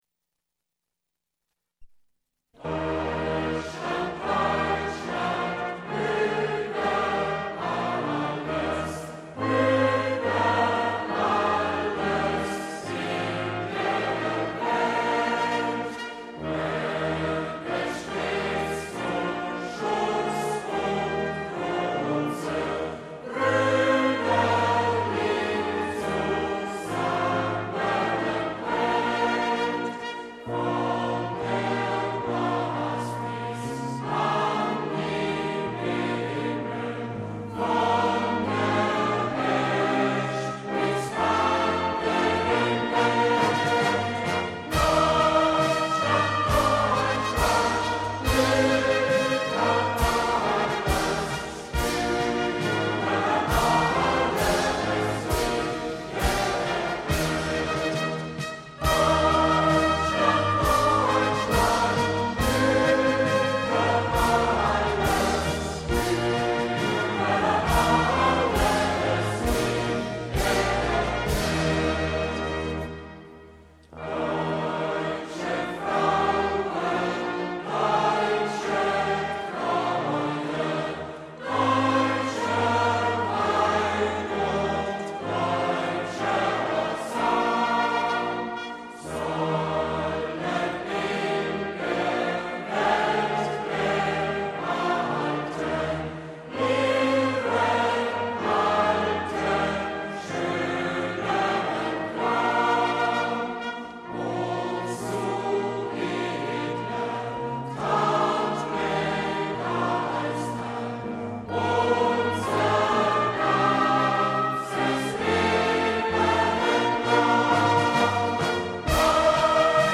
Chor, 3 Strophen